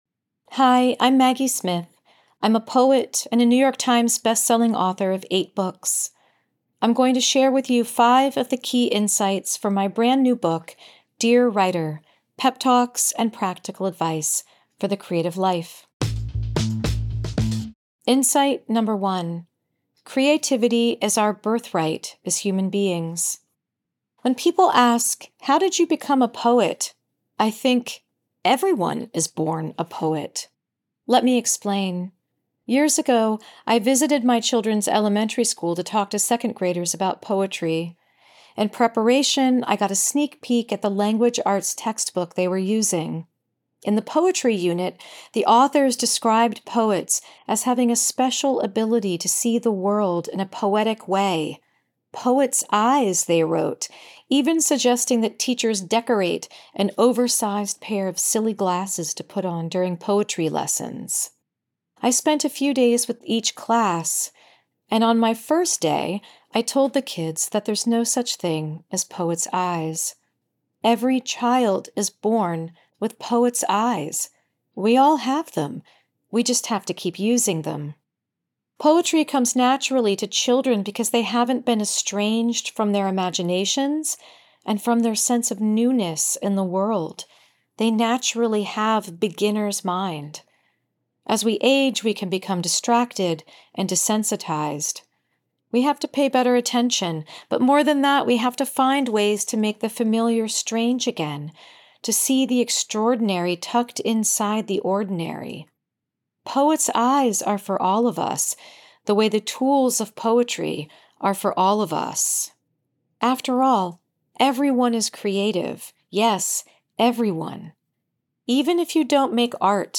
Listen to the audio version—read by Maggie herself—in the Next Big Idea App.